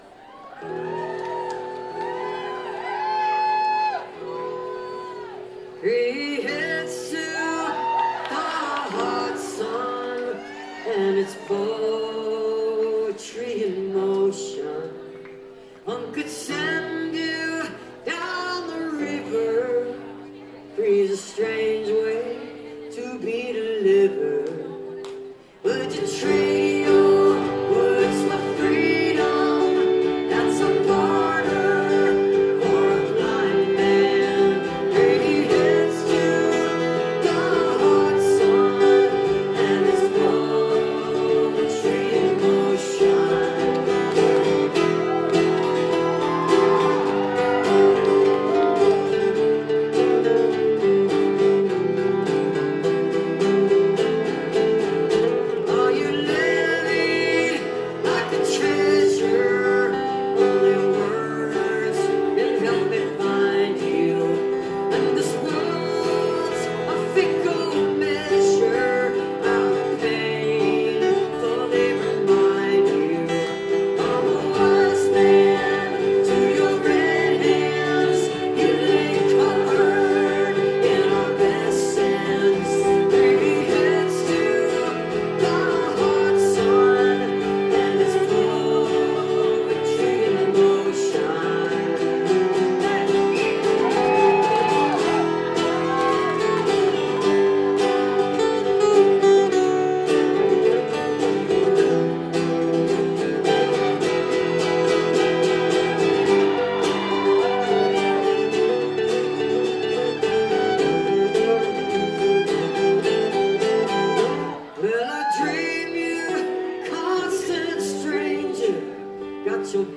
live stream on twitch